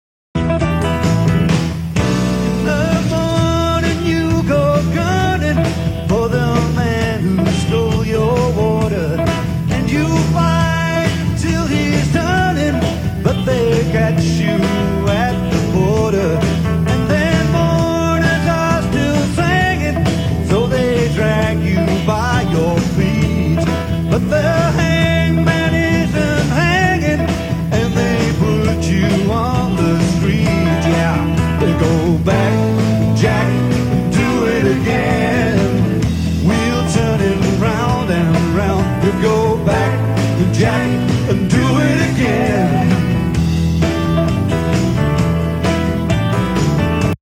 View the picture Play the sample Electric guitar